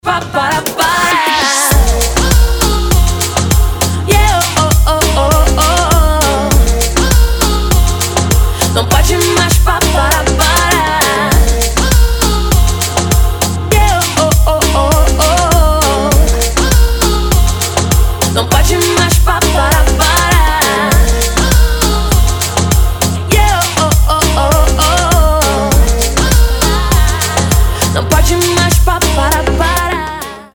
• Качество: 320, Stereo
поп
dance
Electronic